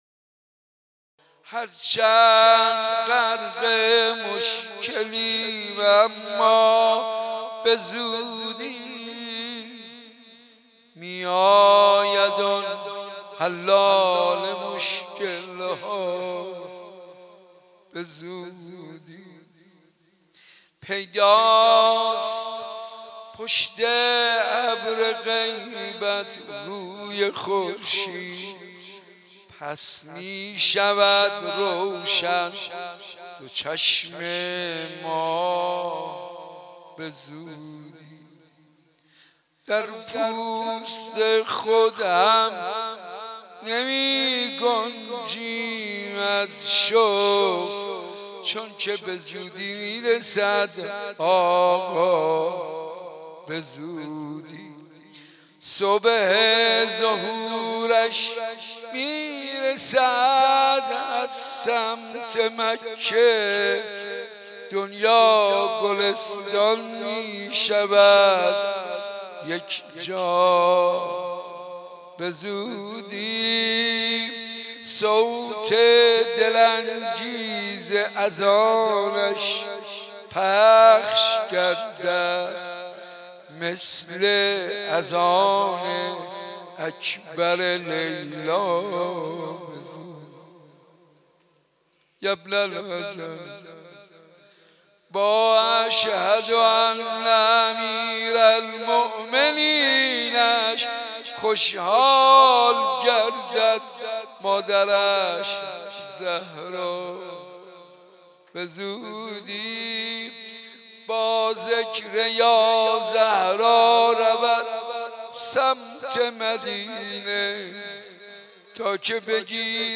حسینیه صنف لباس فروشان با مداحی حاج منصور ارضی